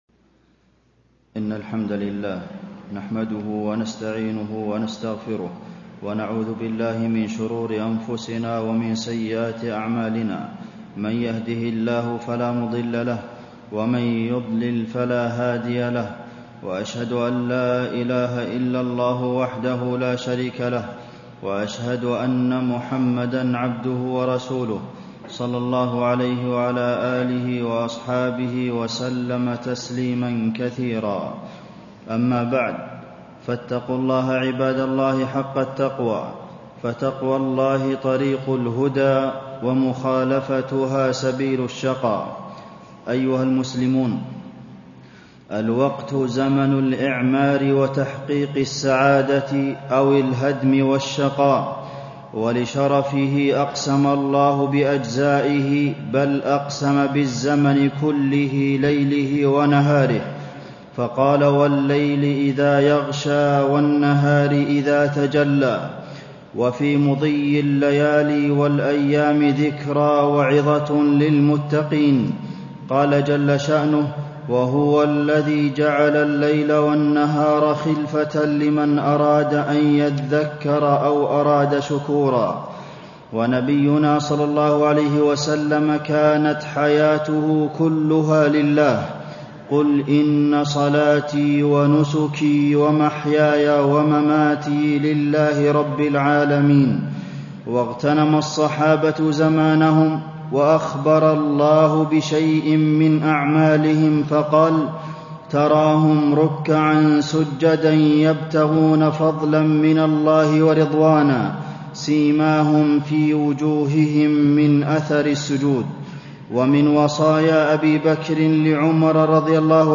تاريخ النشر ١٥ شعبان ١٤٣٥ هـ المكان: المسجد النبوي الشيخ: فضيلة الشيخ د. عبدالمحسن بن محمد القاسم فضيلة الشيخ د. عبدالمحسن بن محمد القاسم احذر إضاعة الوقت